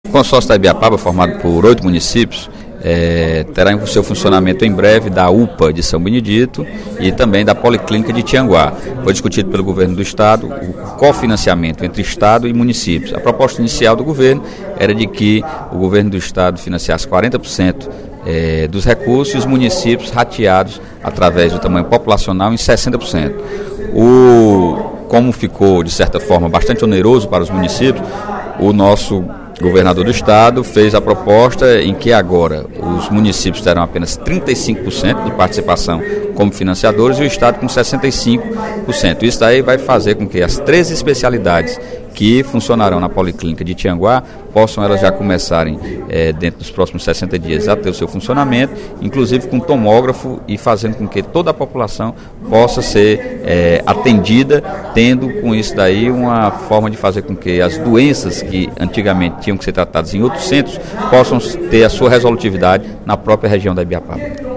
O deputado Sérgio Aguiar (PSB) fez pronunciamento, nesta terça-feira (11/06), para informar que o Governo do Estado irá financiar 65% dos encargos para o funcionamento da UPA de São Benedito e policlínica de Tianguá.